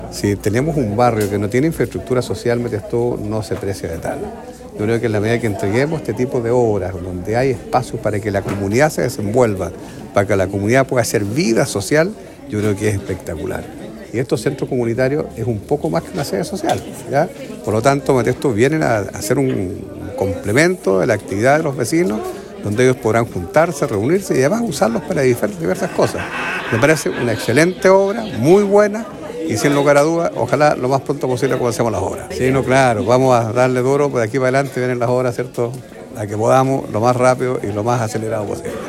El alcalde Jaime Bertin destacó la relevancia de estas obras para el fortalecimiento de las organizaciones locales e indicó que es fundamental contar con espacios que permitan el desarrollo de las actividades comunitarias y deportivas, y a la vez estos centros serán un aporte clave para avanzar en el crecimiento y fortalecimiento de las agrupaciones locales.